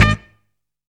PERKY STAB.wav